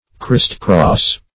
Christcross \Christ"cross`\, n.